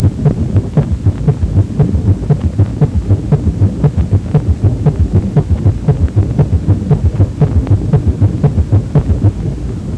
เสียงหัวใจ (Heart sound)